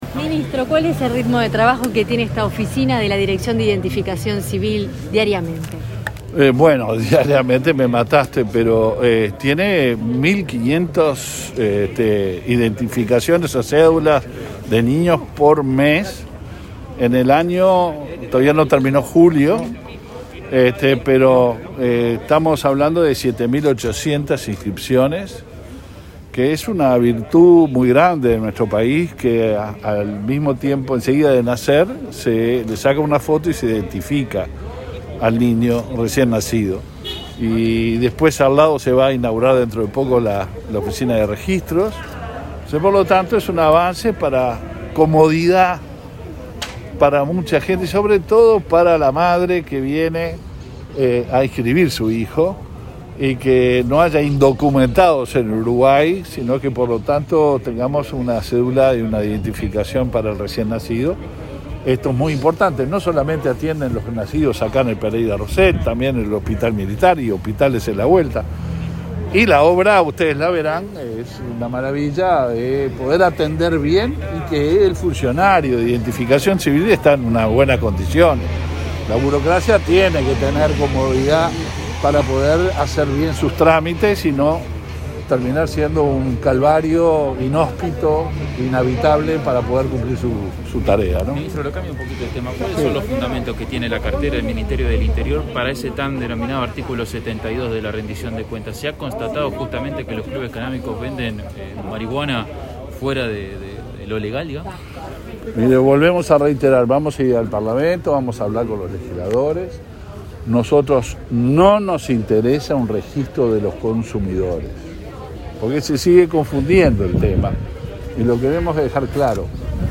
El ministro del Interior, Luis Alberto Heber, participó en inauguración de un local de la Dirección Nacional de Identificación Civil 13/07/2021 Compartir Facebook X Copiar enlace WhatsApp LinkedIn El ministro del Interior, Luis Alberto Heber, participó de la inauguración de un local de la Dirección Nacional de Identificación Civil en el hospital Pereira Rossell.